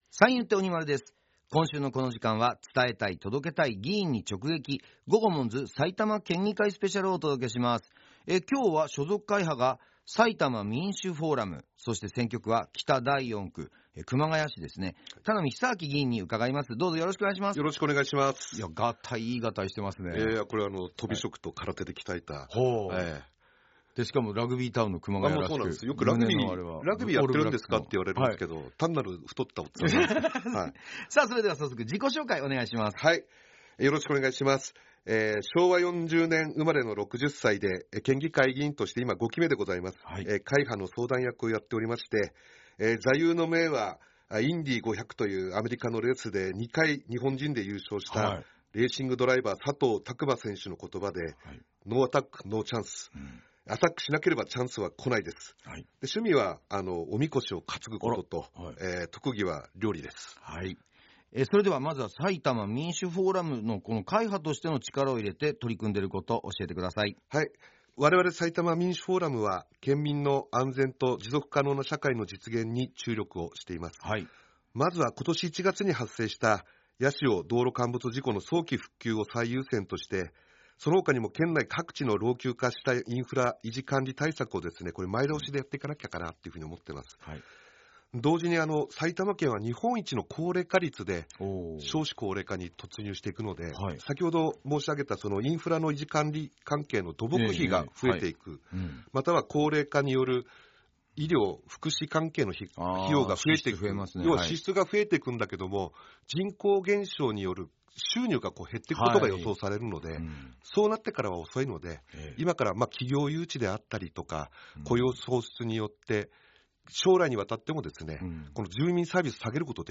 県議会議長や主要会派の議員が「GOGOMONZ」パーソナリティーで落語家の三遊亭鬼丸さんと、所属会派の紹介、力を入れている分野、議員を志したきっかけ、地元の好きなところなどについて軽快なトークを展開しました。
11月10日（月曜日）と11月11日（火曜日）にFM NACK5のスタジオにてラジオ収録が行われました。